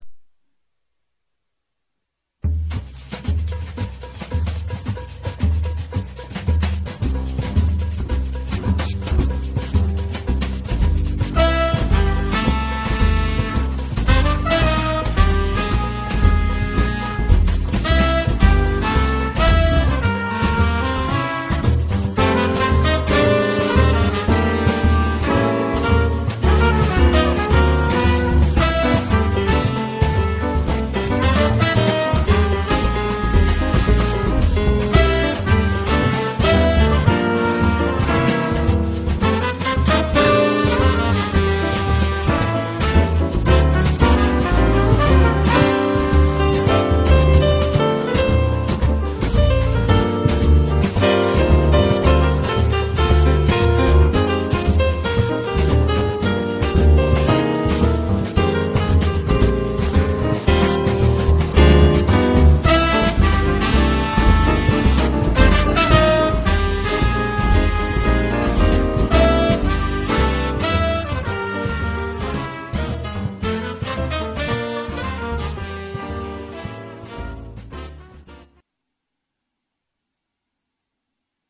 Drums
Trumpet
Tenor Saxophone
Piano
Bass
Percussion